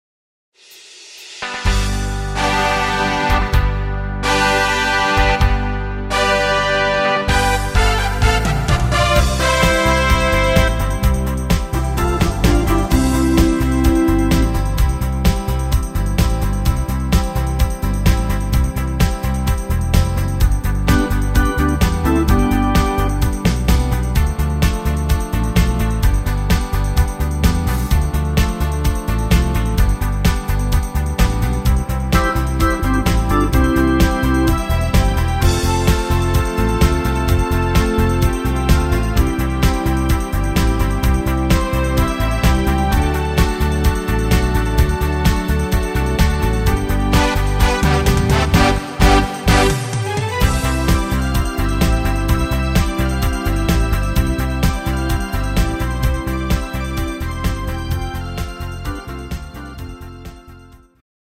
Rhythmus  Discofox
Art  Deutsch, Schlager 2000er